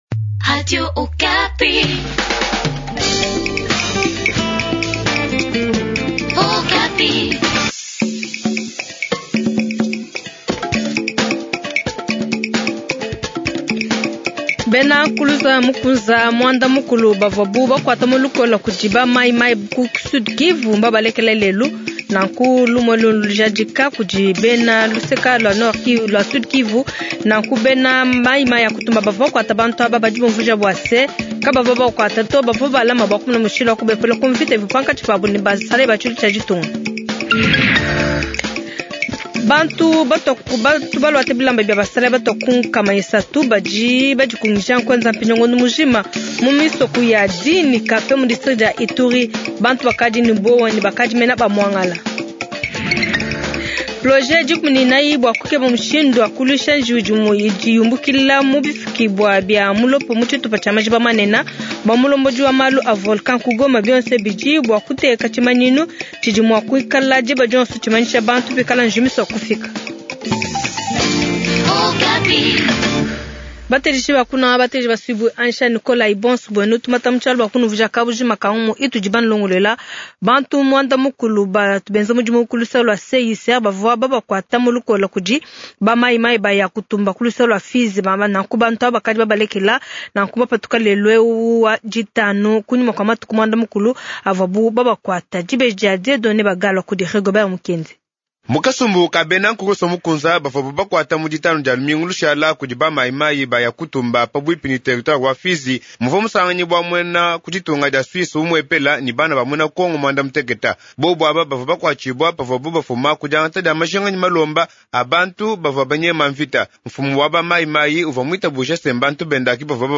Journal tshiluba du soir